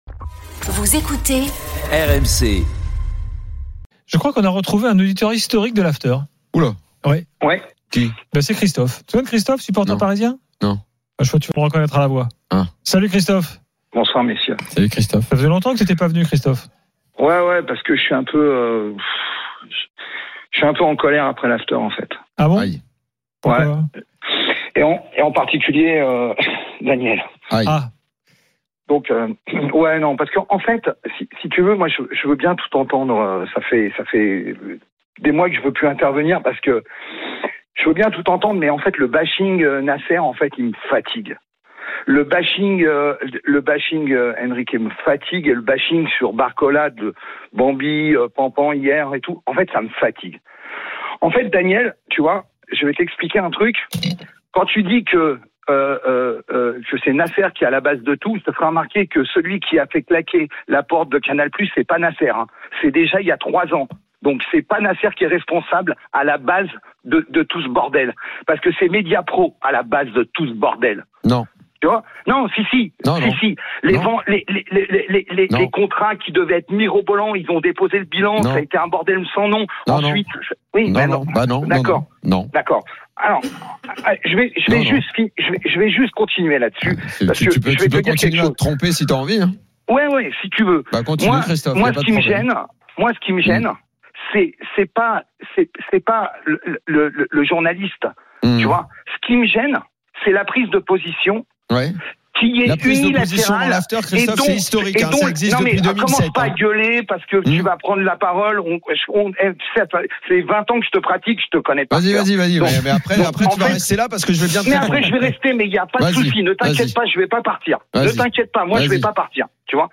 Chaque jour, écoutez le Best-of de l'Afterfoot, sur RMC la radio du Sport ! L’After foot, c’est LE show d’après-match et surtout la référence des fans de football depuis 19 ans !